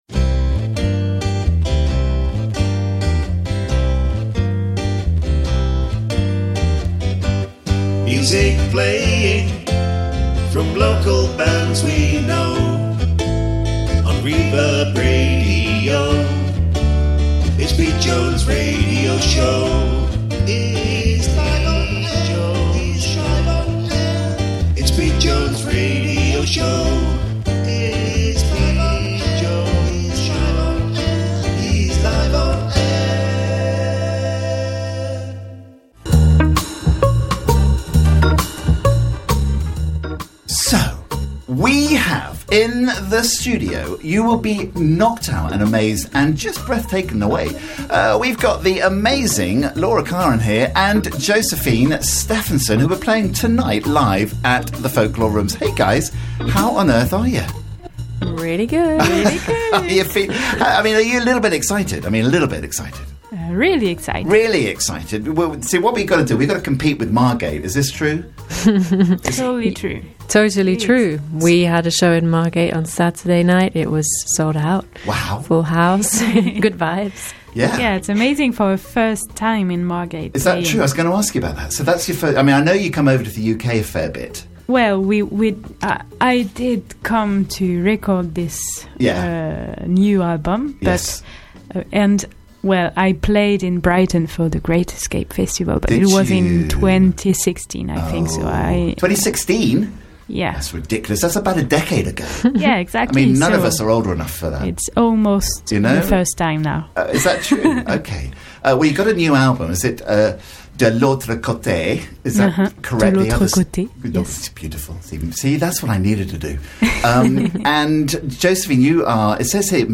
Lots of chat and three tracks played live for us: